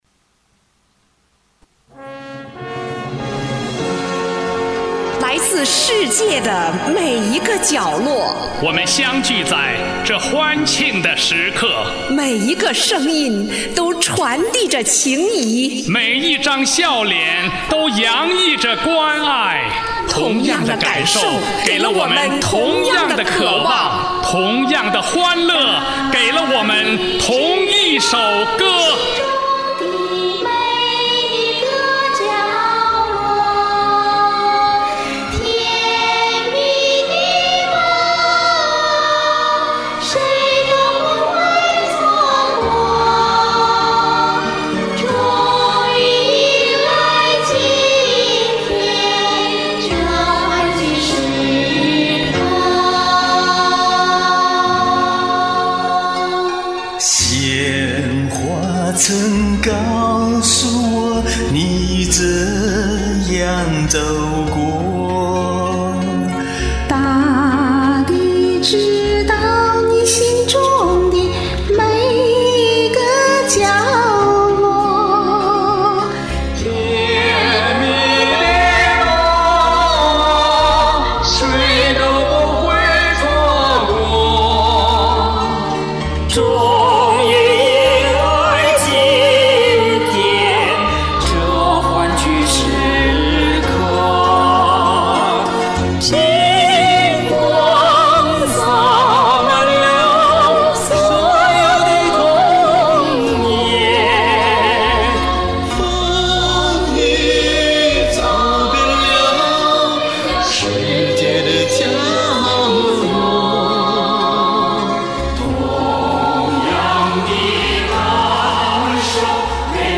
领唱/合唱人员（按加入先后次序）：